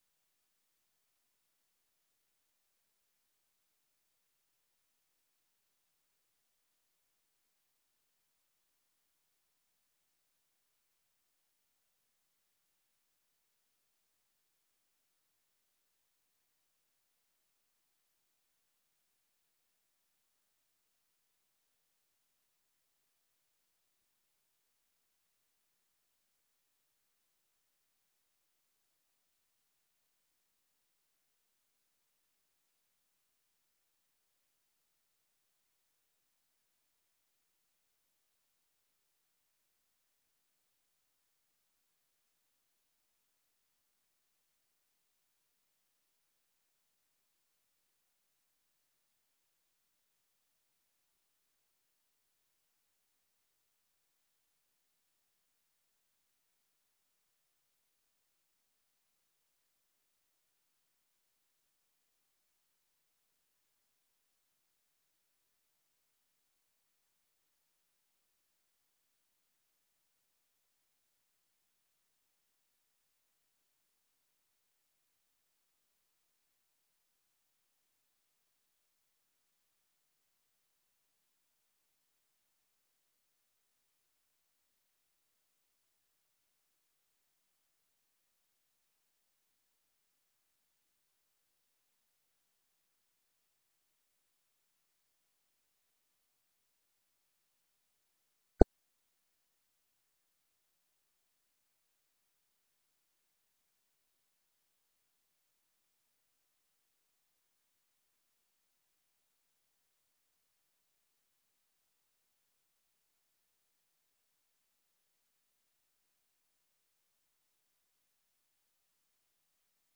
ፈነወ ትግርኛ ብናይ`ዚ መዓልቲ ዓበይቲ ዜና ይጅምር ። ካብ ኤርትራን ኢትዮጵያን ዝረኽቦም ቃለ-መጠይቓትን ሰሙናዊ መደባትን ድማ የስዕብ ። ሰሙናዊ መደባት ሓሙስ፡ መንእሰያት/ ጥዕና